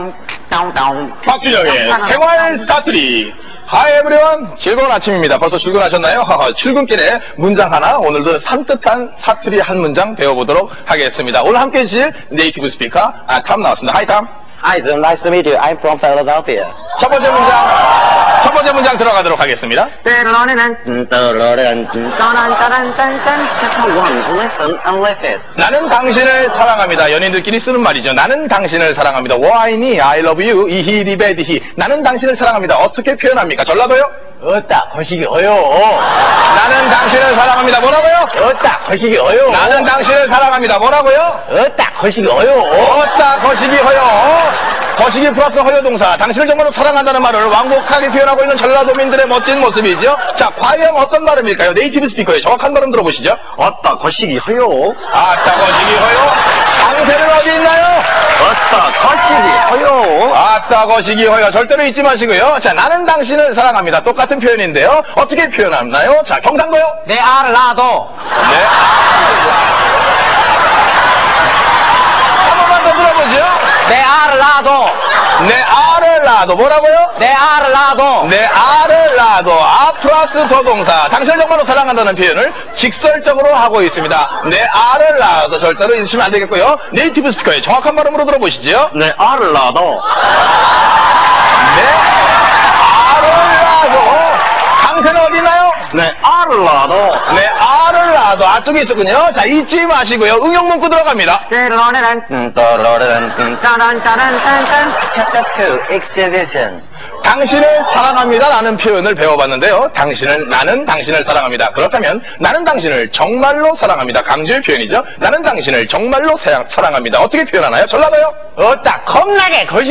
볼랜드포럼: 생활 사투리 한마디! 대박